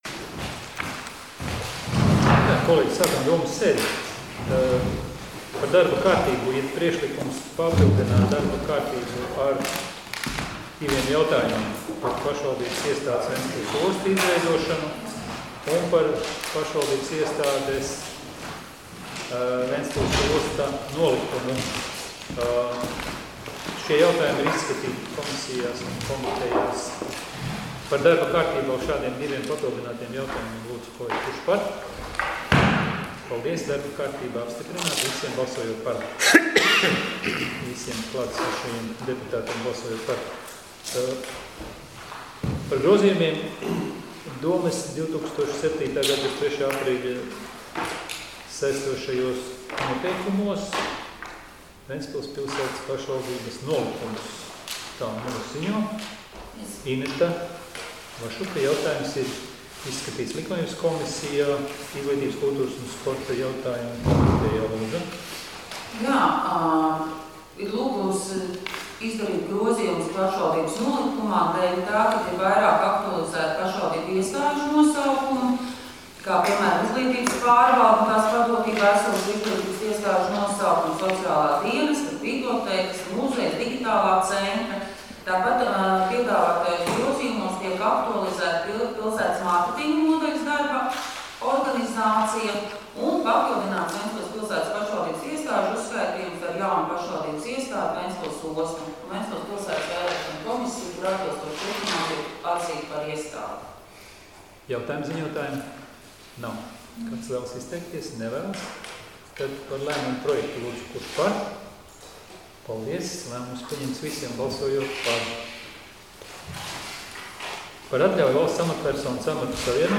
Domes sēdes 19.11.2020. audioieraksts